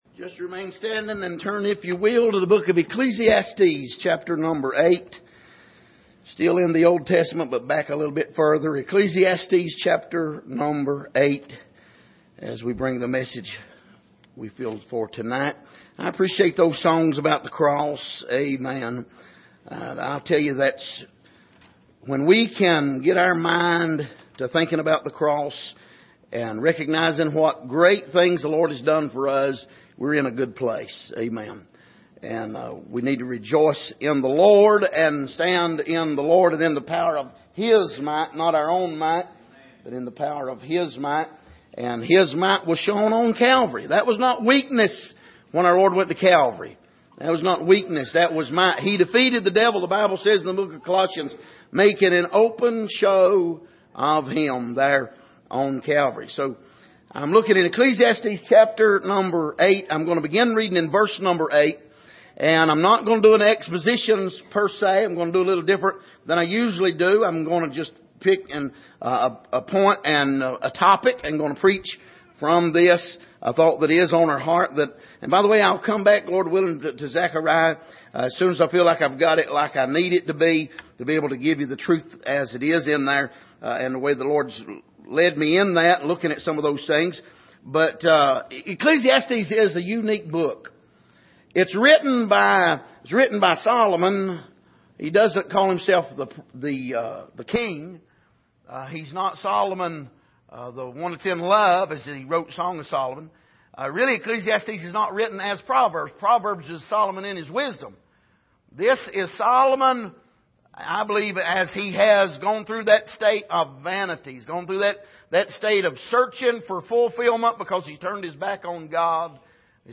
Passage: Ecclesiastes 8:8-10 Service: Sunday Evening